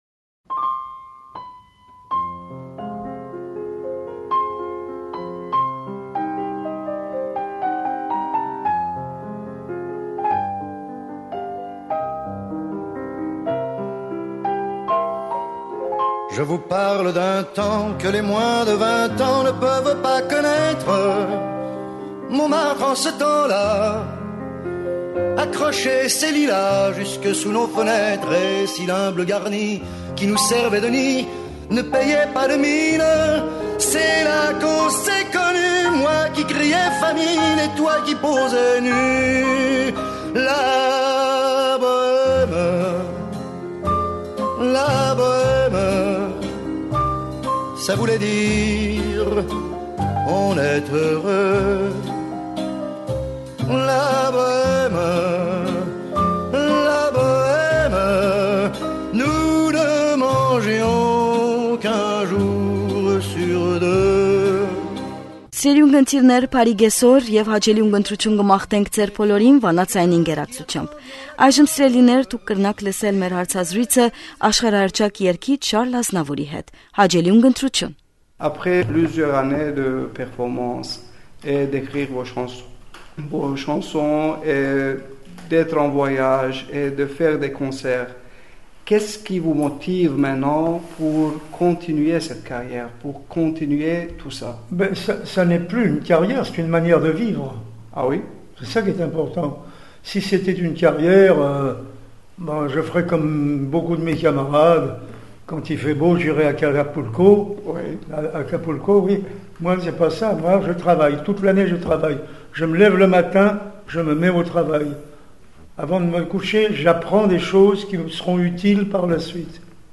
Հարցազրոյցը կարելի է լսել «Վանայ ձայն»-էն:
Երէկ «Վանայ ձայն» ձայնասփիւռի կայանը հարցազրոյց մը ունեցաւ աշխարհահռչակ երգիչ Շարլ Ազնաւուրին հետ: